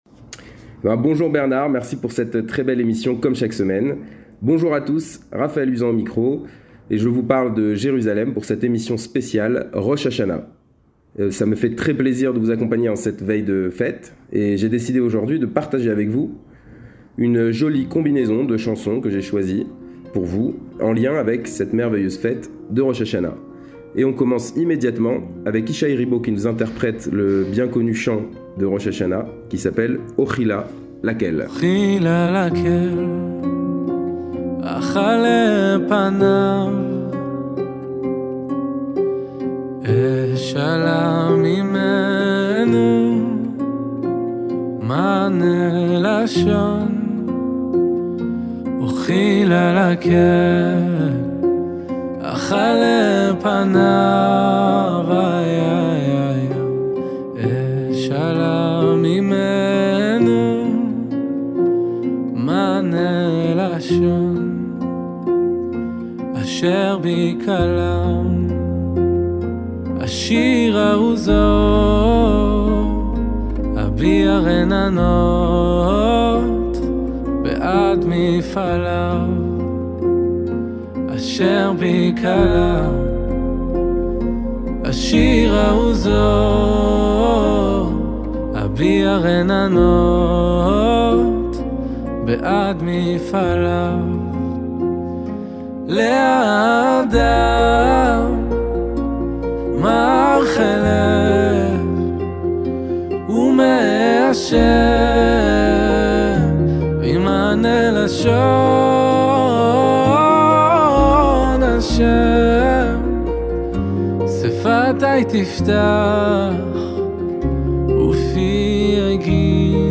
Le meilleur de la musique juive, tous les vendredis après-midi juste après Kabalat shabat, aux alentours de 17h20 !